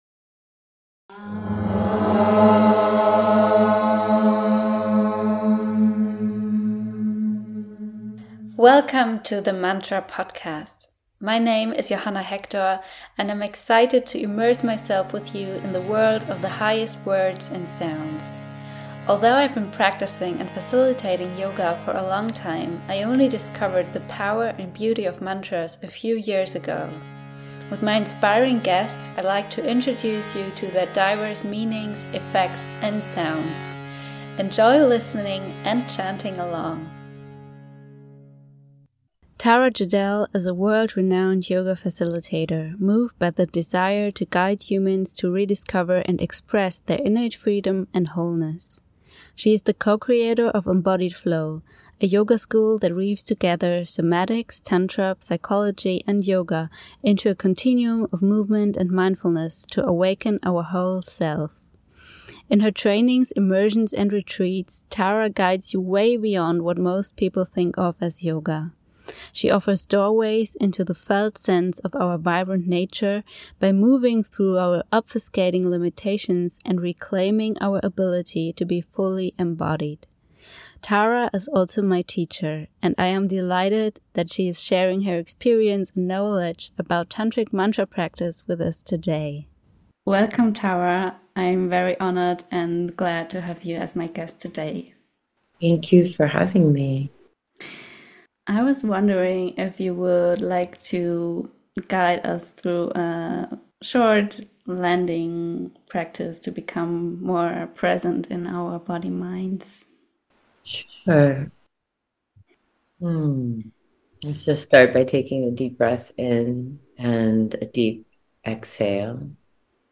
Beschreibung vor 9 Monaten A conversation about the tantric practice of using the energy behind our thoughts, understanding destructive behavior and what a healthy sequence of emotions looks like.